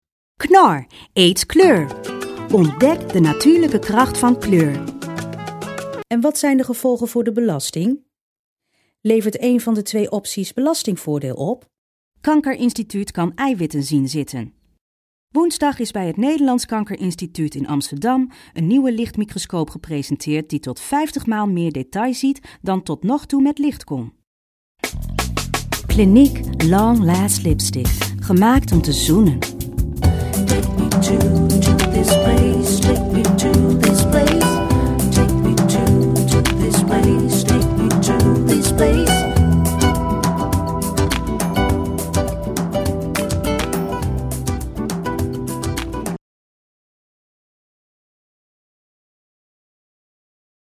Sprechprobe: eLearning (Muttersprache):
Sprechprobe: Werbung (Muttersprache):